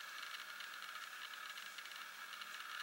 计算机 " 笔记本电脑键盘
描述：在我的笔记本电脑的键盘上打字。您还可以听到计算机内部风扇的声音。
Tag: 类型打字 电脑 键盘 笔记本电脑 按键